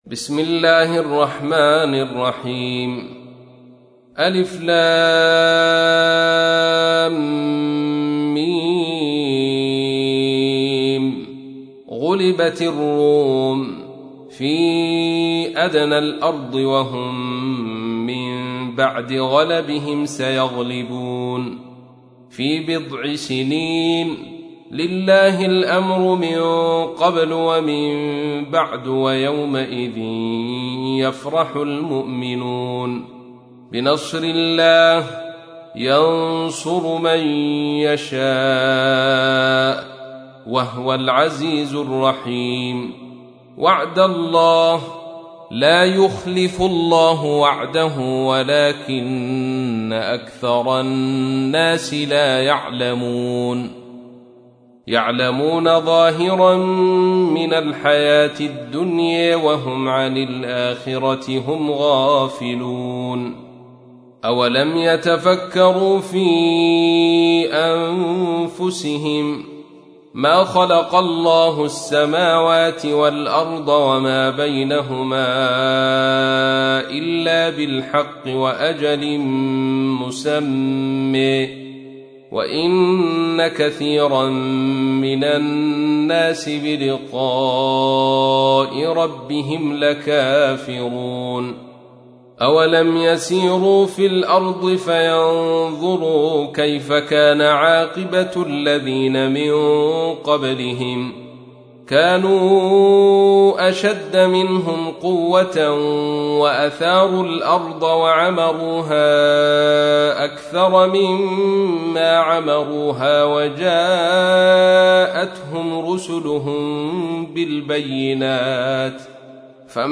تحميل : 30. سورة الروم / القارئ عبد الرشيد صوفي / القرآن الكريم / موقع يا حسين